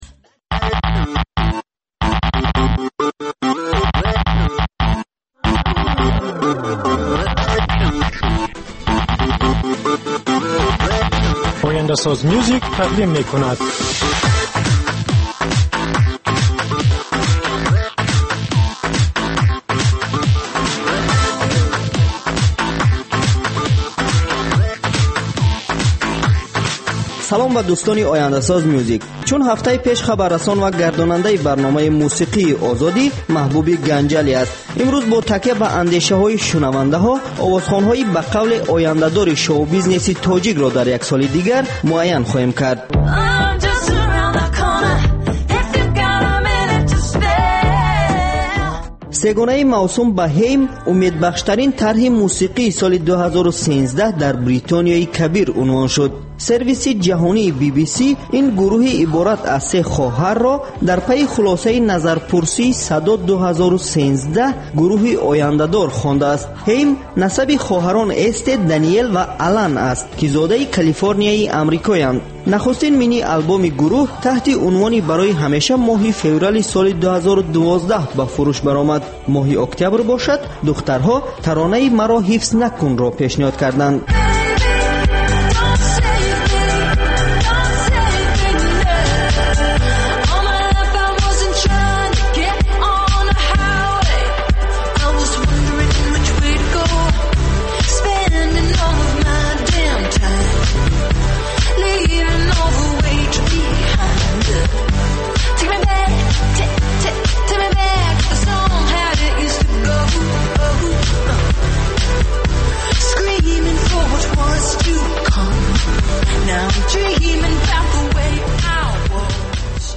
Ахбори фарҳангӣ, гуфтугӯ бо овозхонони саршинос, баррасии консерт ва маҳфилҳои ҳунарӣ, солгарди ходимони ҳунар ва баррасии саҳми онҳо.